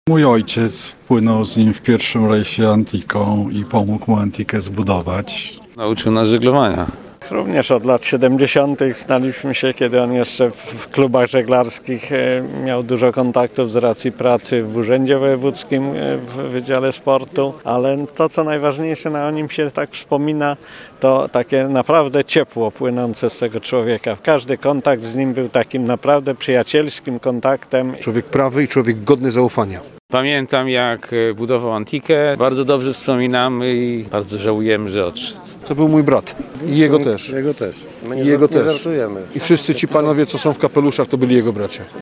pogrzeb-dzwiek-.mp3